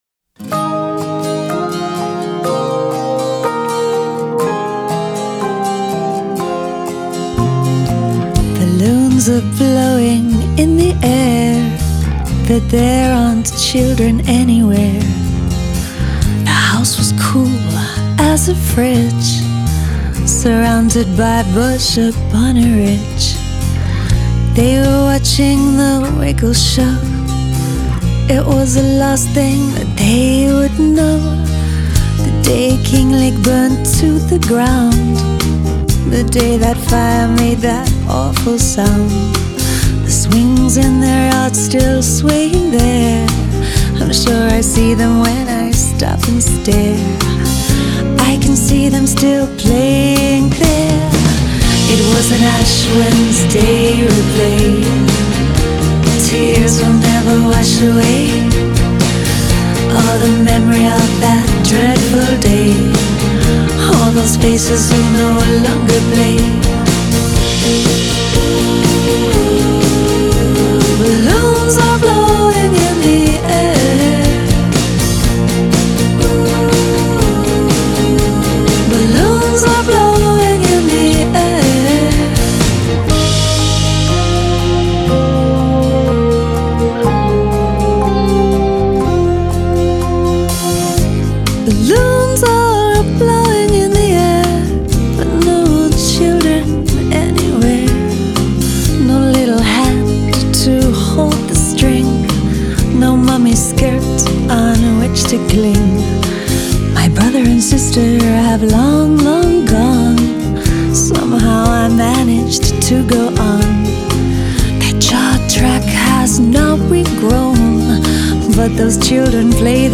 piano and backing vocals
guitar
drums